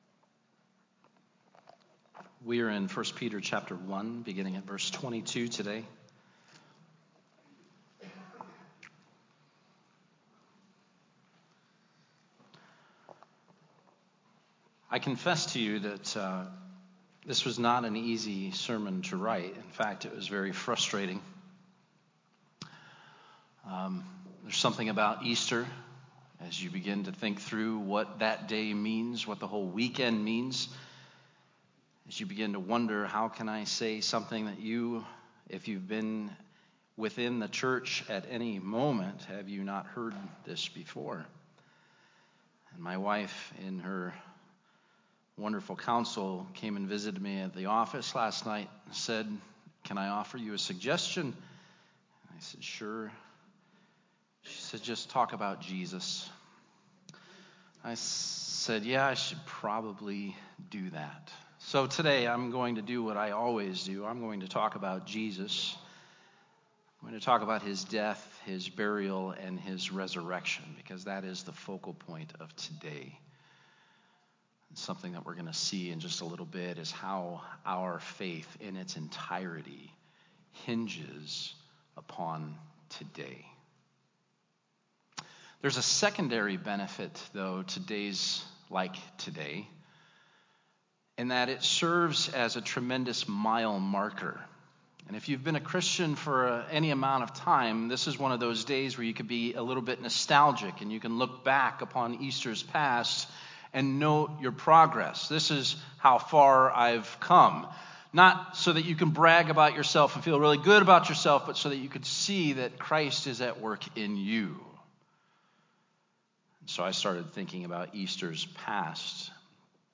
Our Easter message from 1 Peter 1:22-2:3...